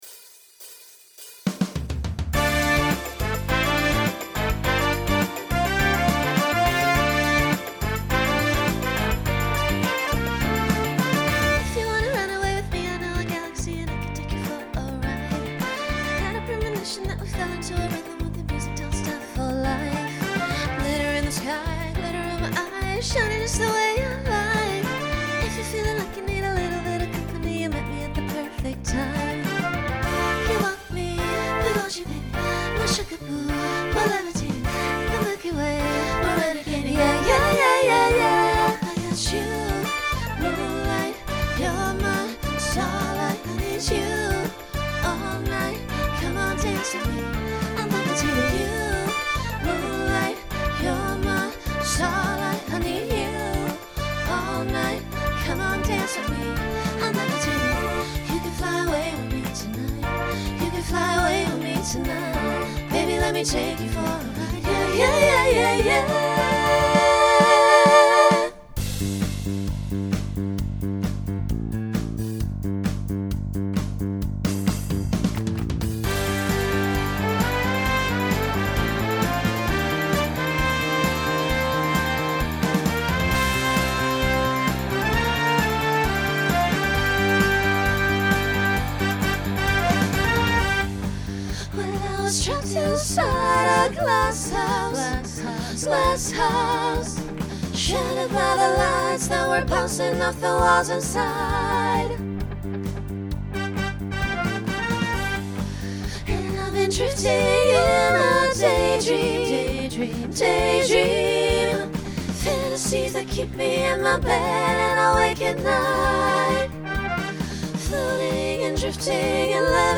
Pop/Dance
Voicing SSA